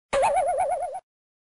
Звуки головокружения
Короткое комичное недоумение